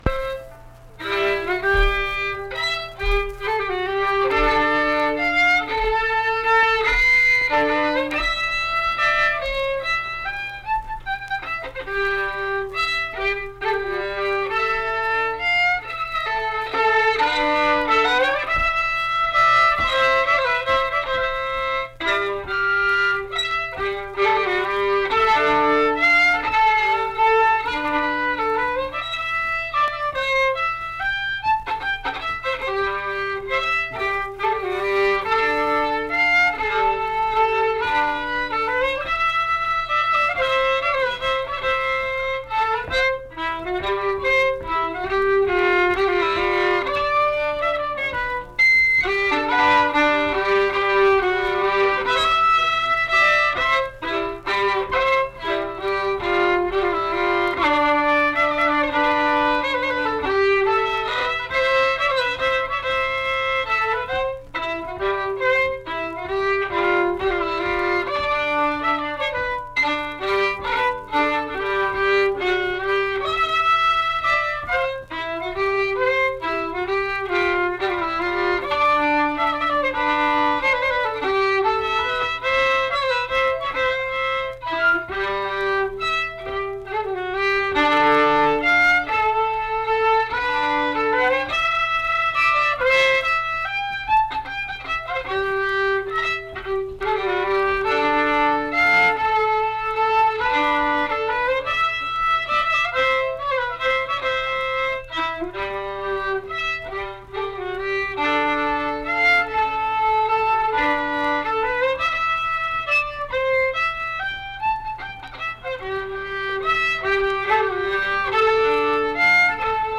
Unaccompanied fiddle music
Instrumental Music
Fiddle